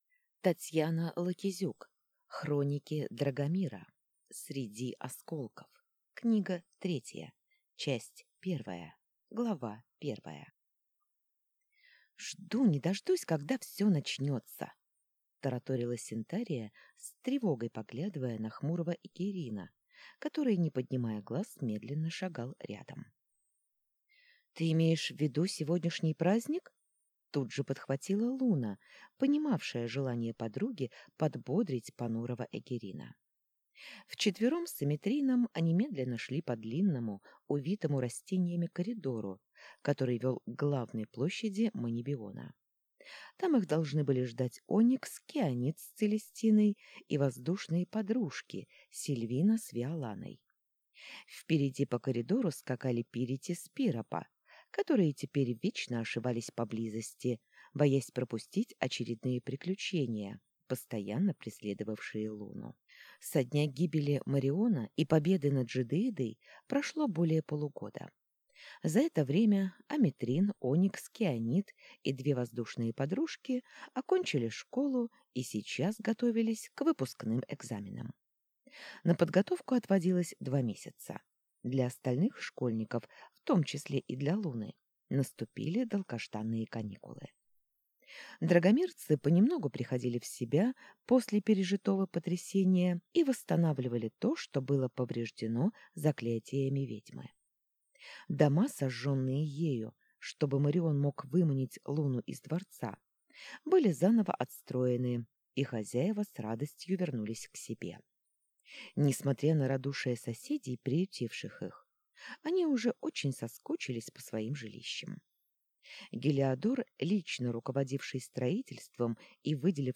Аудиокнига Хроники Драгомира. Книга 3. Среди осколков | Библиотека аудиокниг